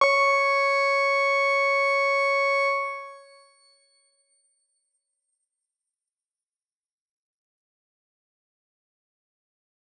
X_Grain-C#5-pp.wav